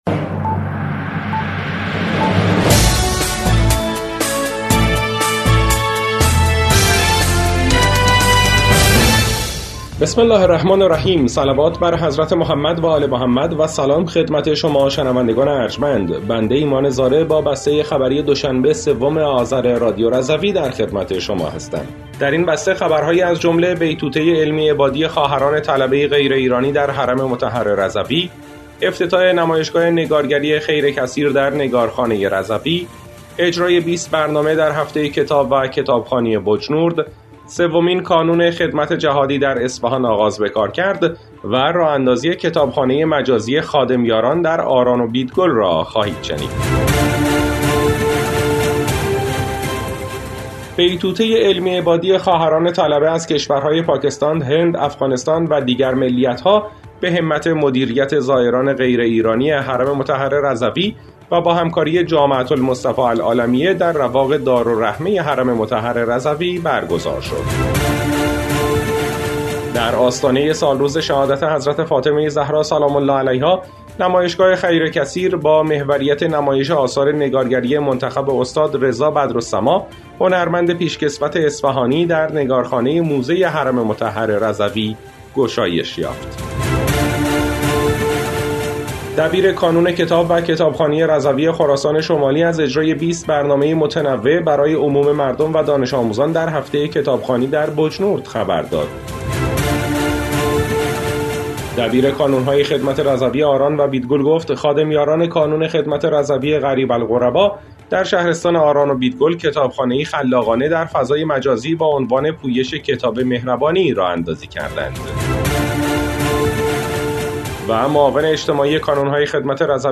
بسته خبری ۳ آذر ۱۴۰۴ رادیو رضوی؛